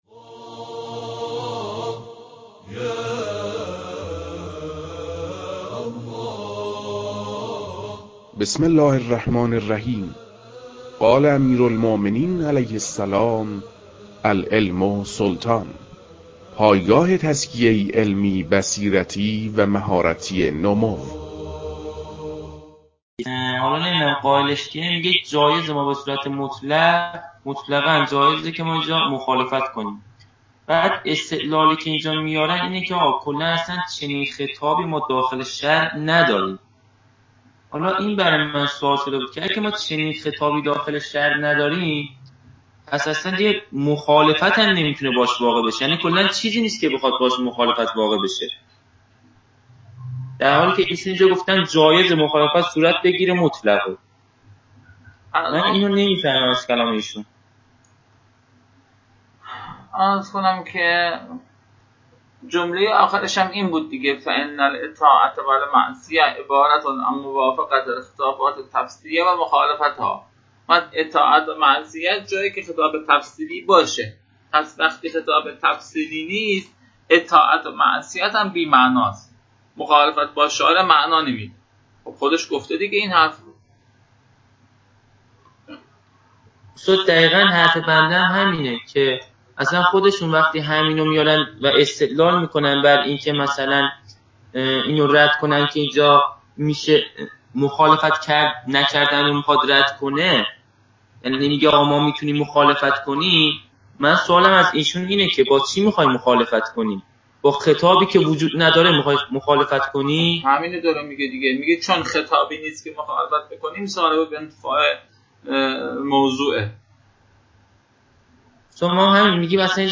تدریس